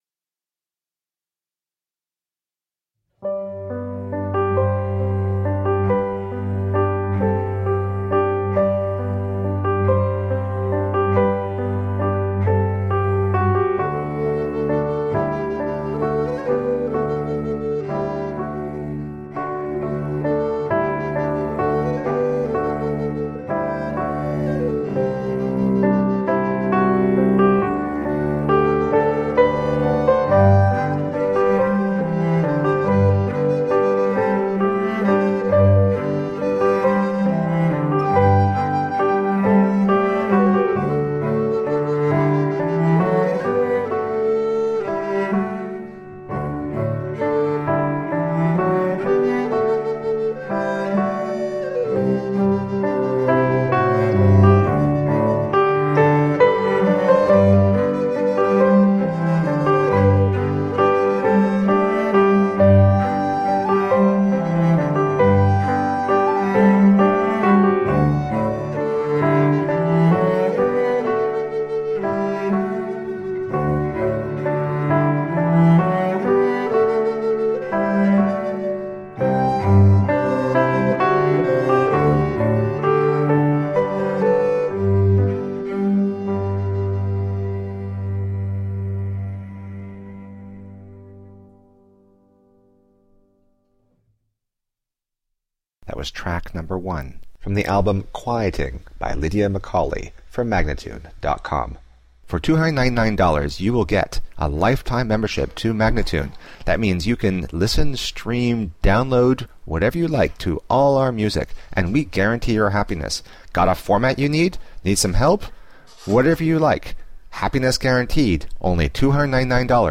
Intriguing piano compositions with dynamic accompaniment.
Tagged as: New Age, Folk, Instrumental